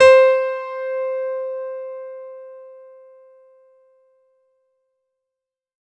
R  C4  DANCE.wav